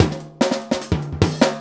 LOOP39--01-R.wav